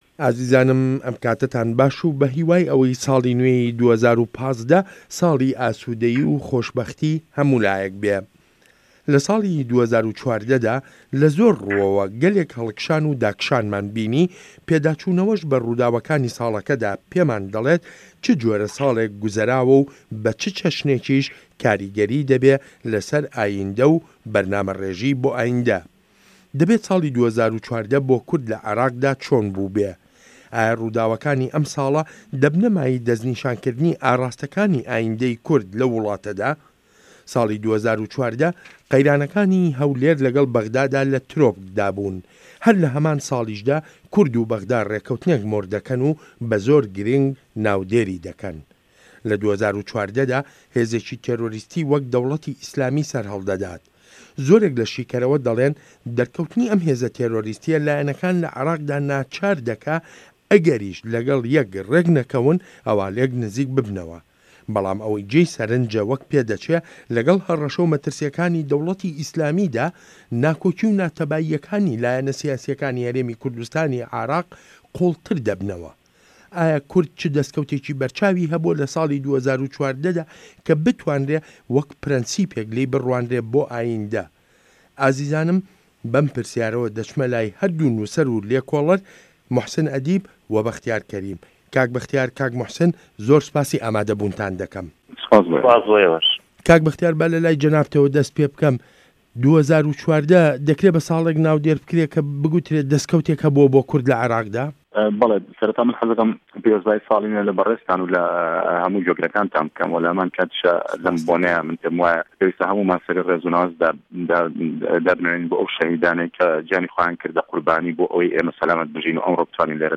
مێزگرد: کورد،2014 ،ئاینده‌